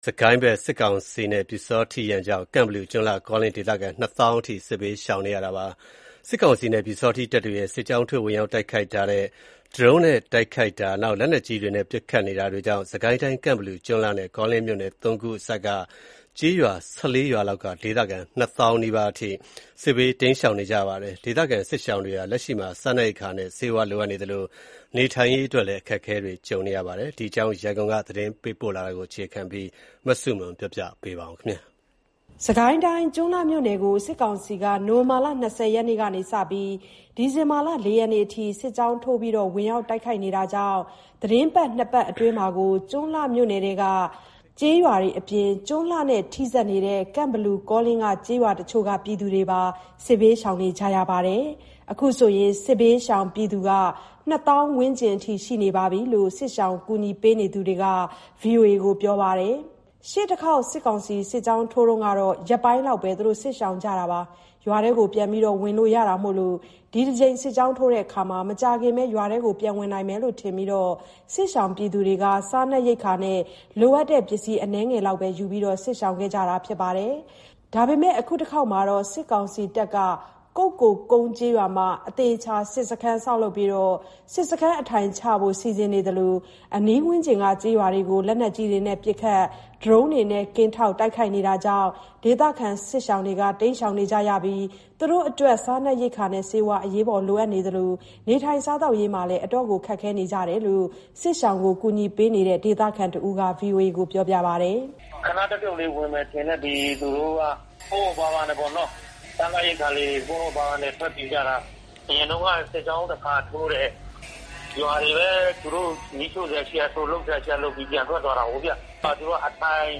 စစ်ကောင်စီက ကုက္ကိုကုန်းရွာမှာ အထိုင်စခန်းချပြီး အနီးဝန်းကျင်က ကျေးရွာတွေကို ဒရုန်းနဲ့ ကင်းထောက် တိုက်ခိုက်နေတာကြောင့် စစ်ဘေးရှောင်တွေ ဒုက္ခရောက်ကြရတဲ့ အခြေအနေကို ဒေသခံတဦးက ခုလိုပြောပြပါတယ်။